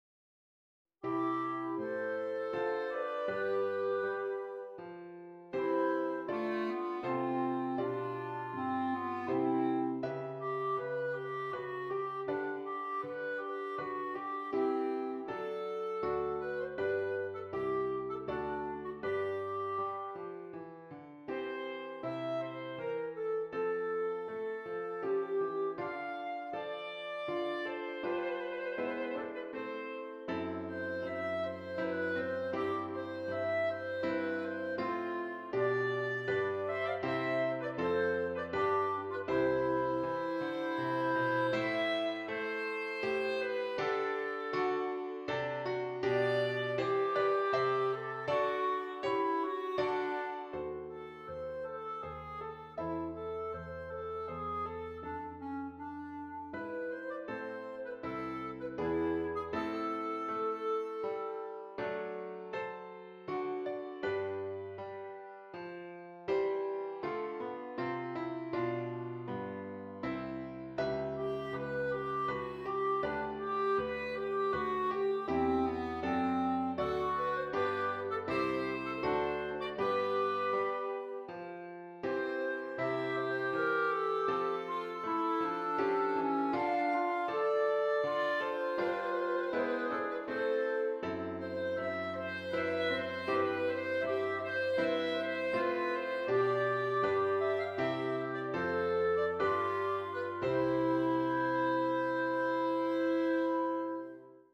2 Clarinets and Piano